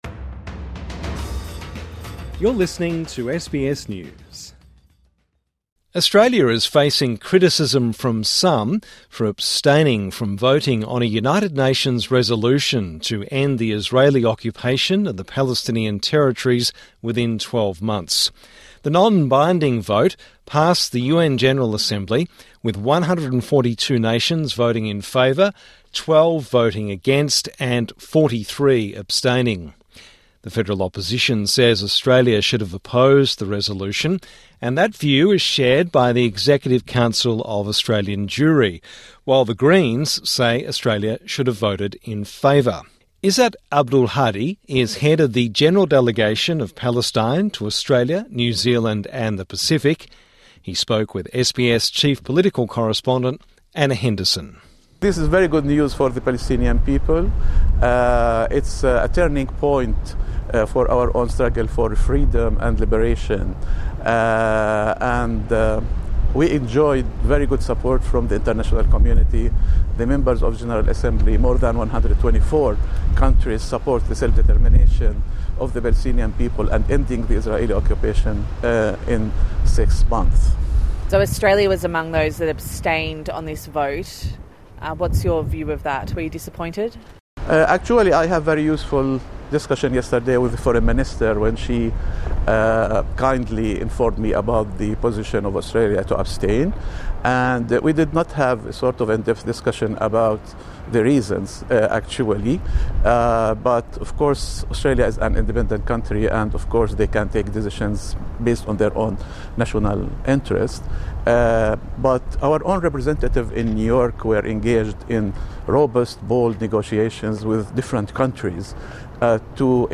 INTERVIEW: Palestinian ambassador to Australia, Izzat Abdulhadi